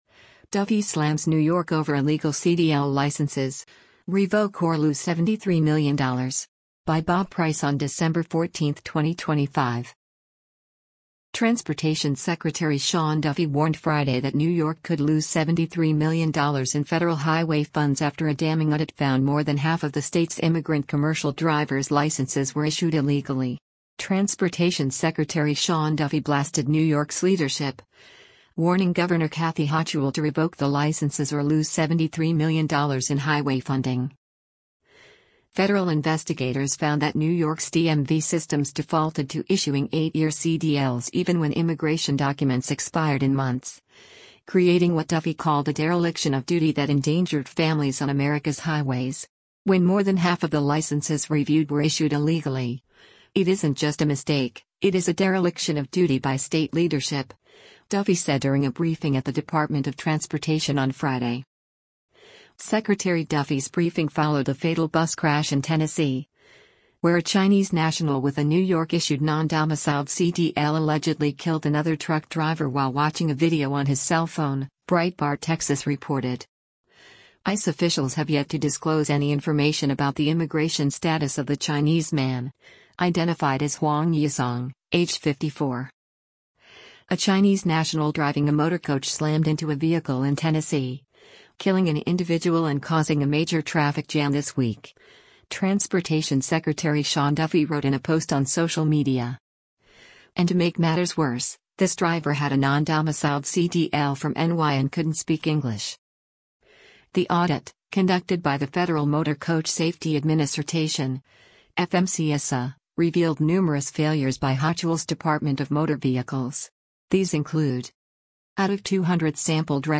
“When more than half of the licenses reviewed were issued illegally, it isn’t just a mistake — it is a dereliction of duty by state leadership,” Duffy said during a briefing at the Department of Transportation on Friday.